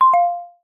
8. notification2